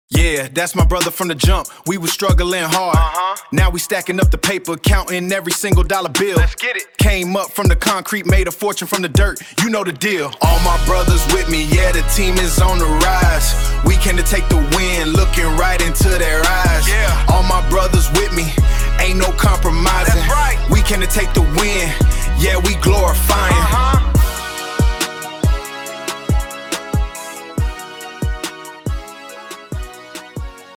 Category:Rap